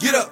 Vox
SouthSide Chant (59)(1).wav